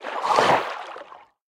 File:Sfx creature nootfish eat 01.ogg - Subnautica Wiki
Sfx_creature_nootfish_eat_01.ogg